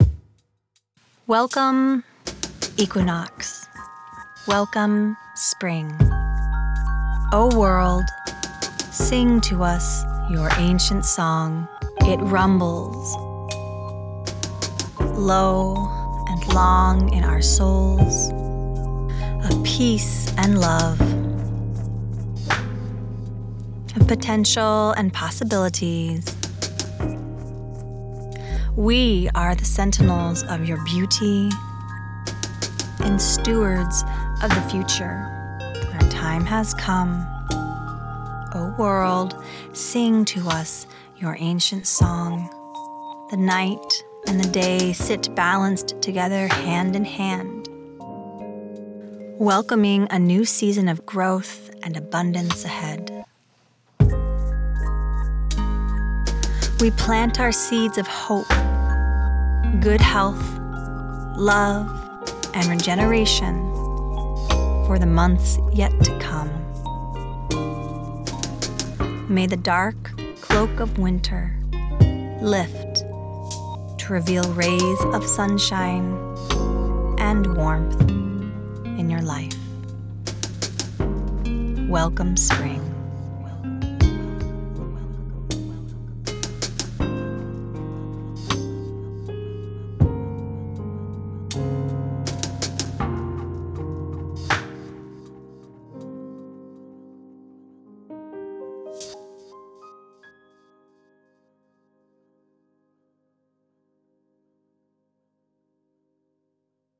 A melody came to me, then the words, and finally, the full chord progression.
I tuned the sounds to an Ab Major 7th chord and used the EQ to carve away any intrusive formants.
piano
I ended up with a vibraphone, a guitar
Once I had carved out the correct EQ spaces for each instrument in the mix, I threw a sub-bass under it all, gave it a filter, and then began the mixing process by zooming in and zooming out.
Here is the song & poetry reading!